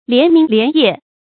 连明连夜 lián míng lián yè
连明连夜发音